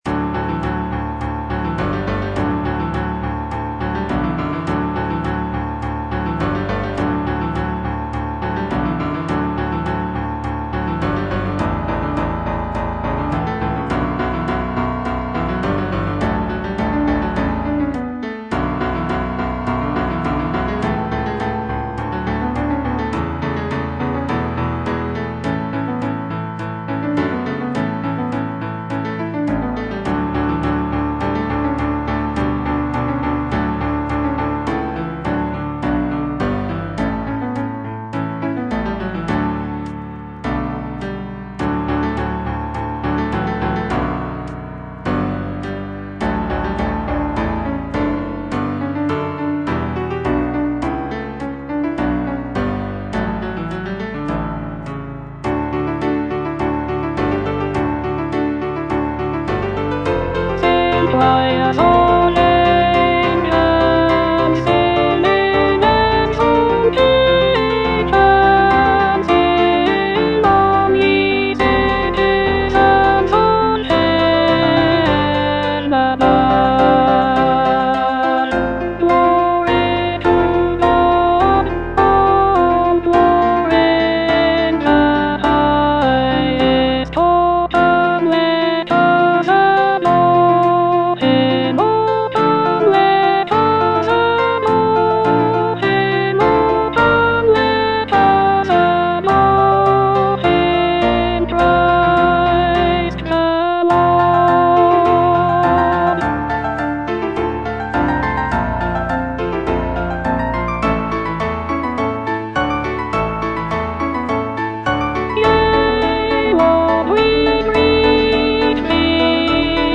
Alto I (Voice with metronome)